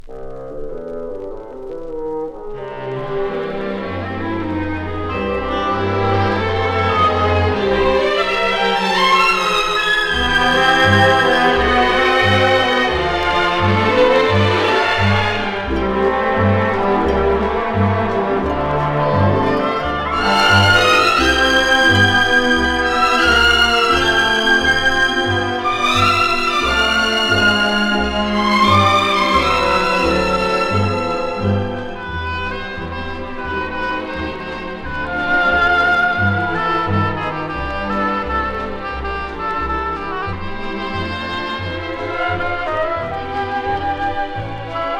Jazz, Pop, Stage & Screen　USA　12inchレコード　33rpm　Mono